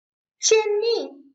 jiànyì
chen i    kiến nghị